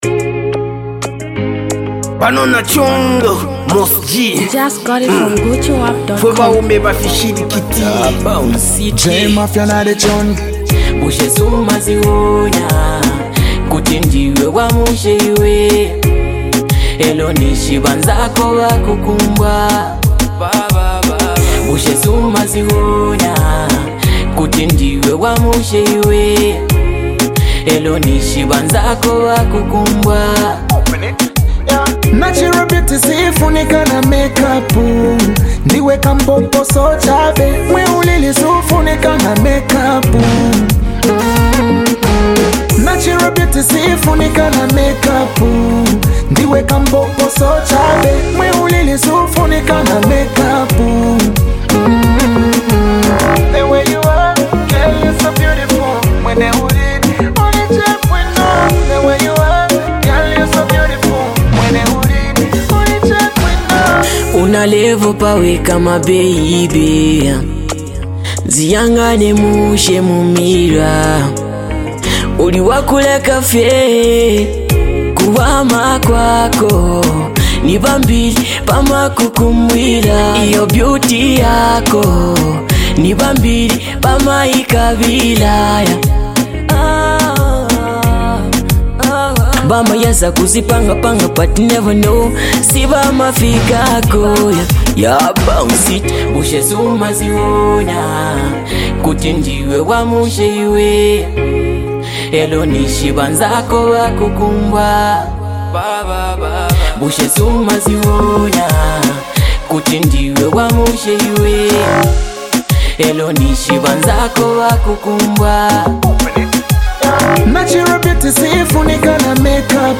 on hardcore rap
on soulful vocals.